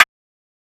snare 1 (kinda rare, not rly).wav